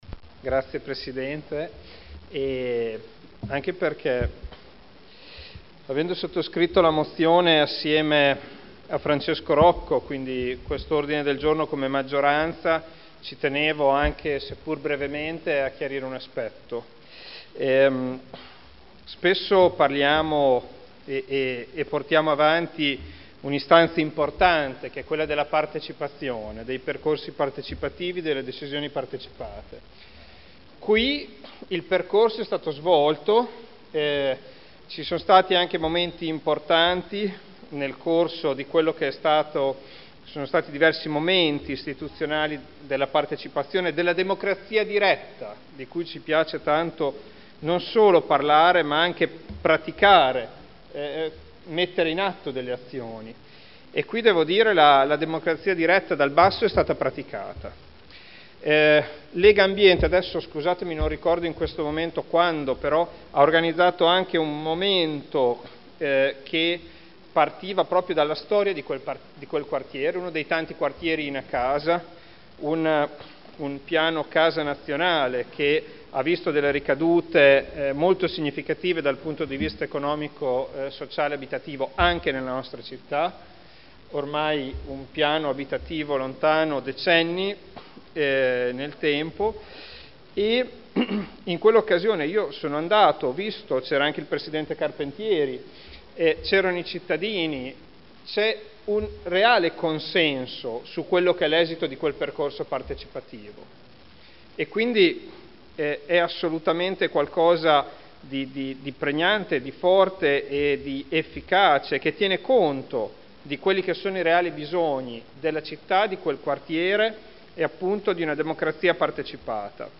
Seduta del 14/01/2014 Dibattito.